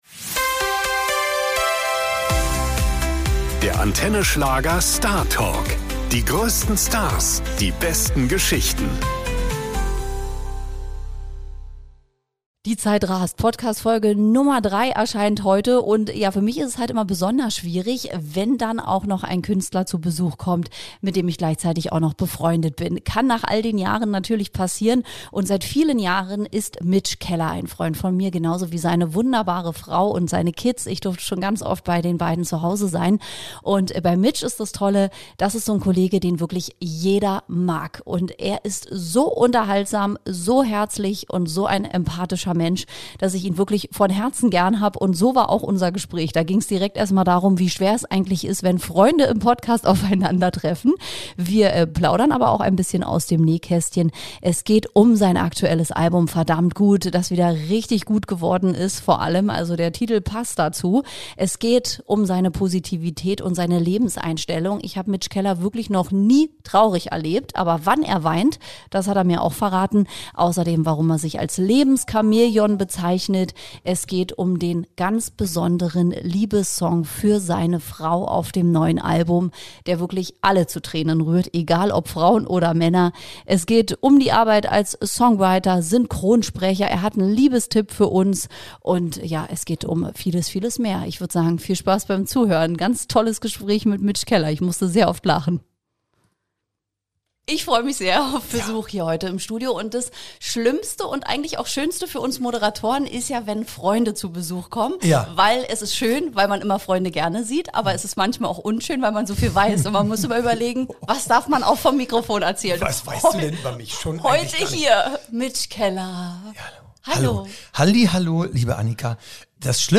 Schon beim Eintreffen fliegen die Sprüche und das Lachen; die Stimmung ist locker, offen und einfach „verdammt gut“ - genau wie sein aktuelles Album!